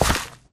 gravel2.ogg